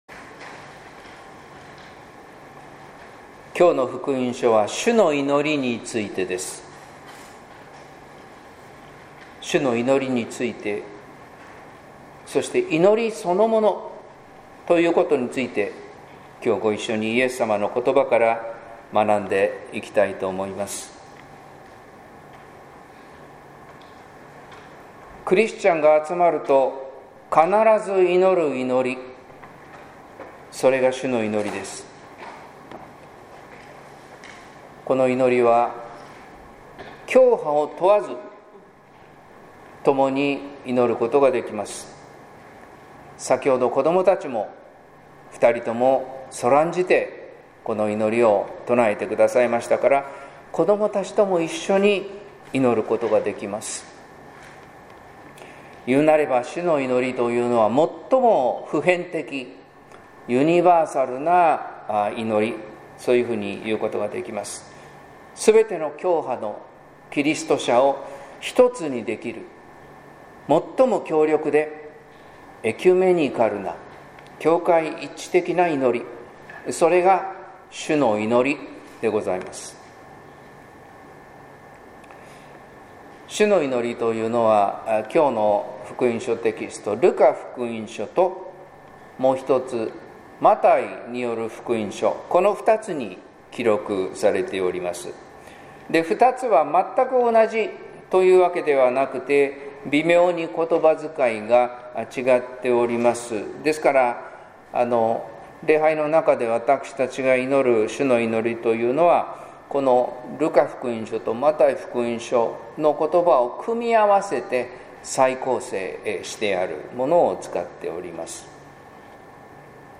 説教「主の祈りを祈り続ける」（音声版）